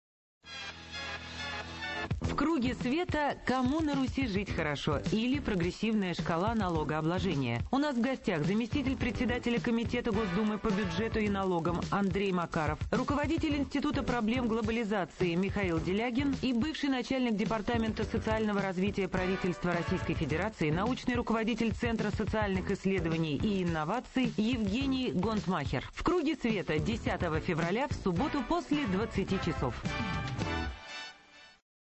программа Светланы Сорокиной
на радио «Эхо Москвы»
Аудио, .mp3: анонс –